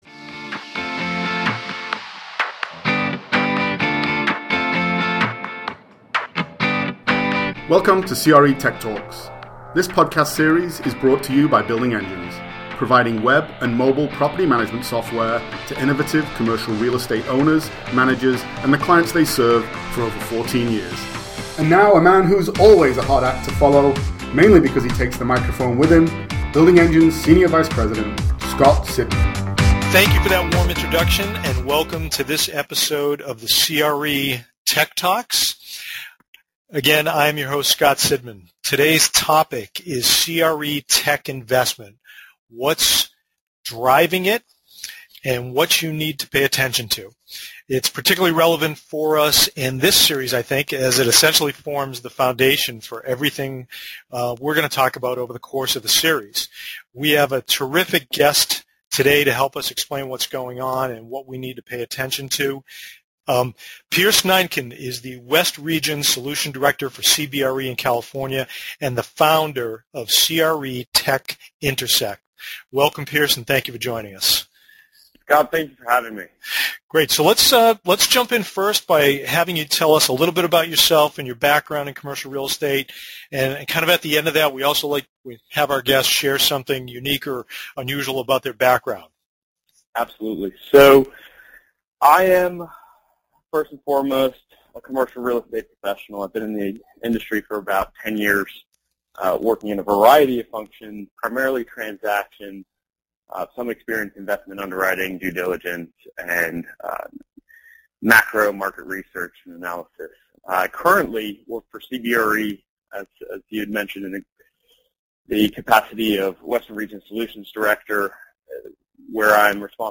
A fast-paced discussion on the rapid explosion of CRE technology and its impact on operations.